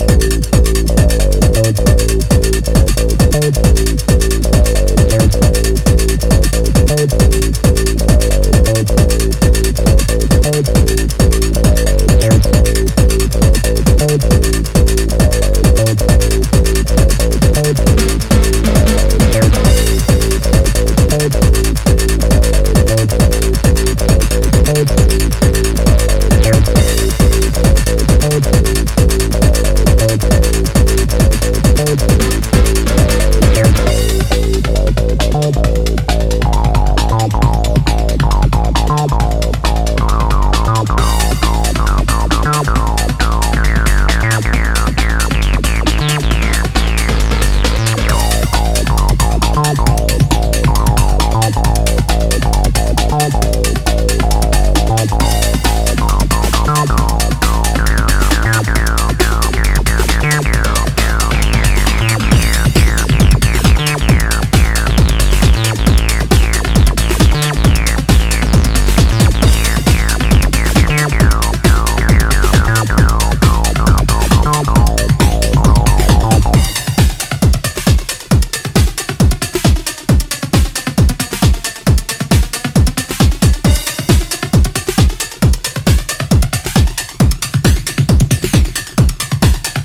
UK tech house pioneers